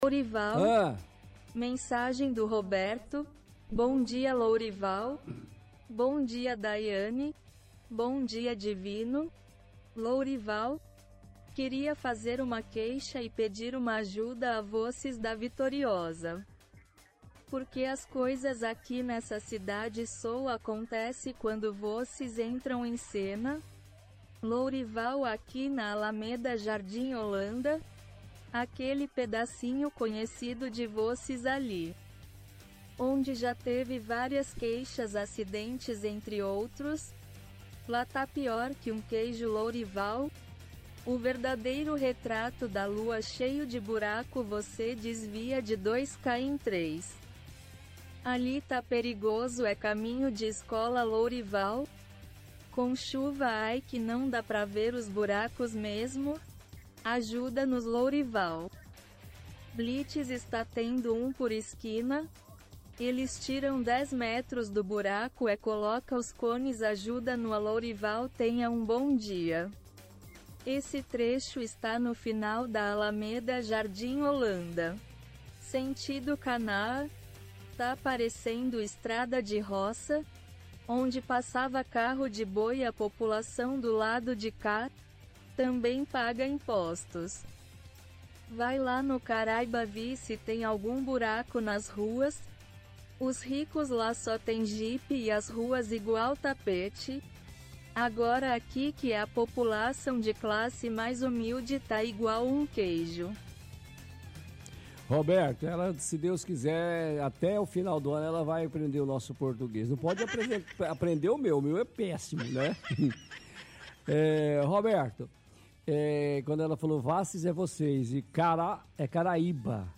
-Ouvinte reclama de buracos no bairro Jardim Holanda, diz que população de lá também paga impostos. Diz que no bairro Karaíba o asfalto está igual um tapete.